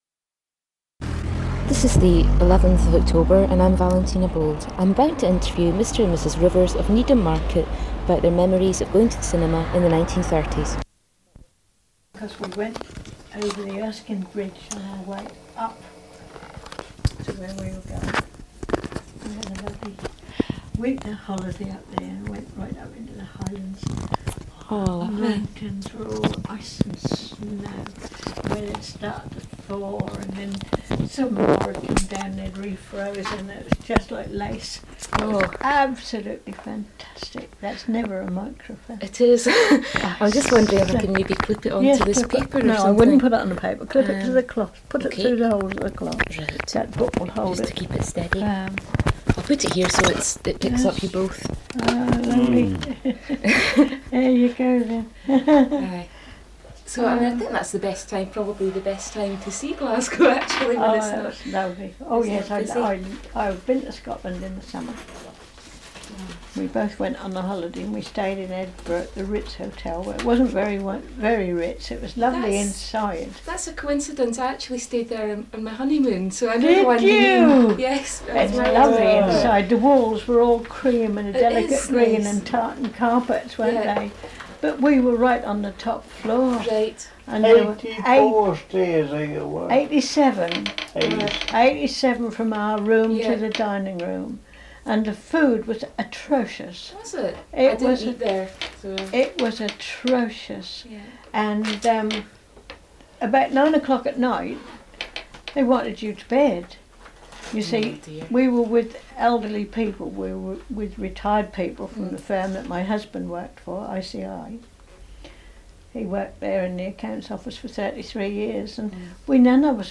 00:00:00 Disclaimer: This interview was conducted in 1995 and concerns memories of 1930s life; as such there may be opinions expressed or words used that do not meet today's norms and expectations.